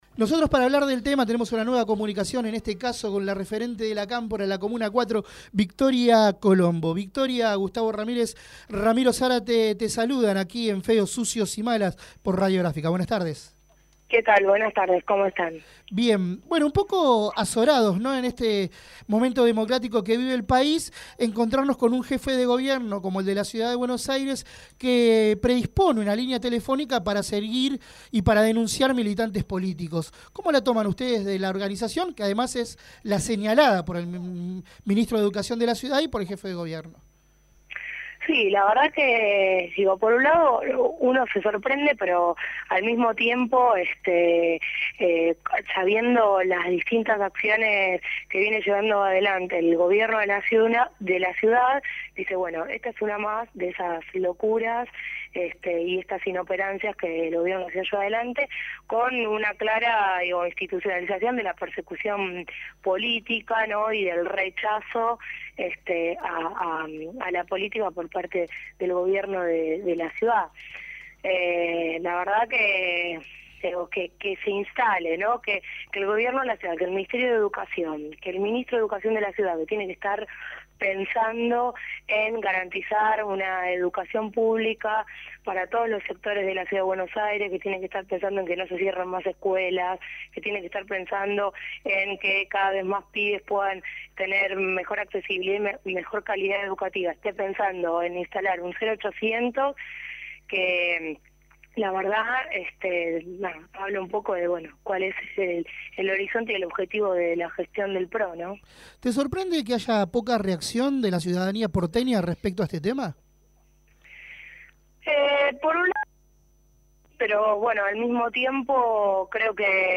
Victoria Colombo, comunera en la comuna 4 por el Frente Para la Victoria y referente de la Cámpora, habló en Feos, Sucios y Malas (Sàbados de 18 a 20hs).